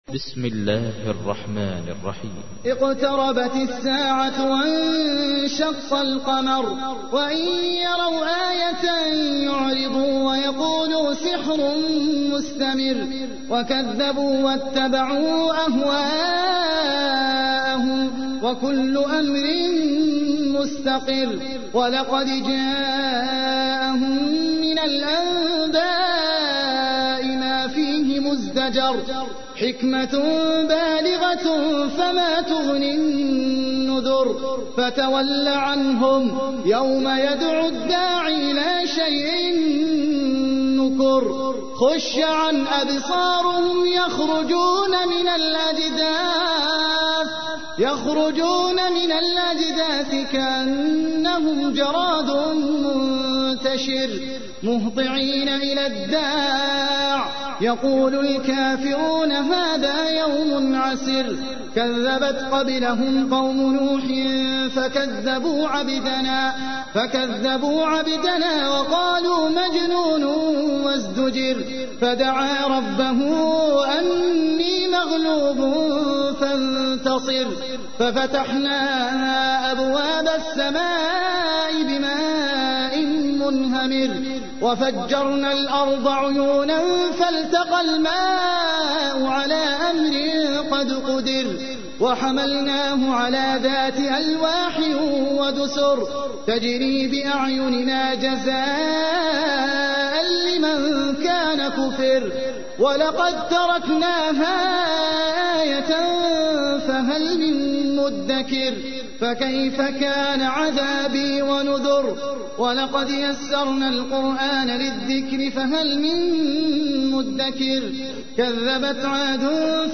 تحميل : 54. سورة القمر / القارئ احمد العجمي / القرآن الكريم / موقع يا حسين